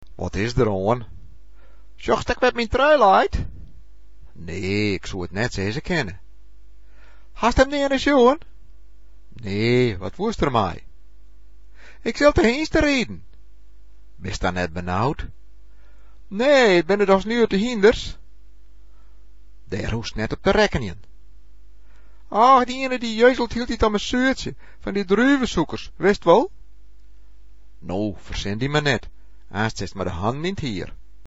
Here’s a recording of conversation in a mystery language.